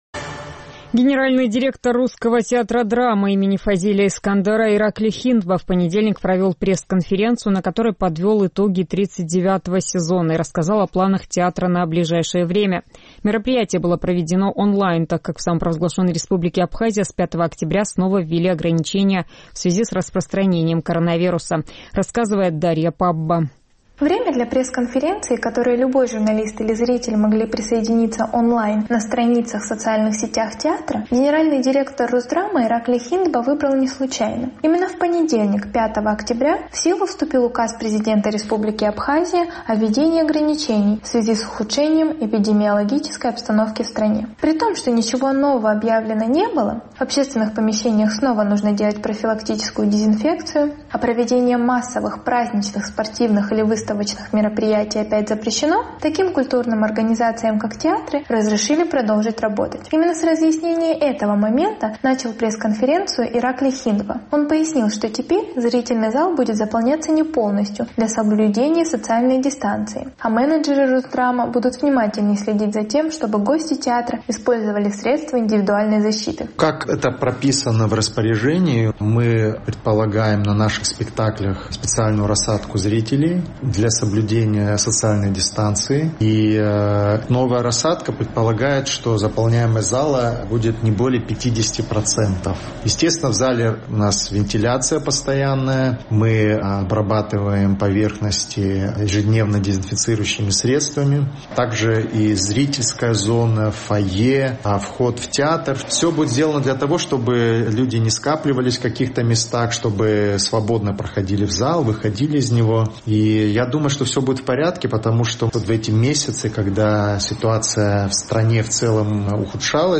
Мероприятие было проведено онлайн, так как в Абхазии с 5 октября снова...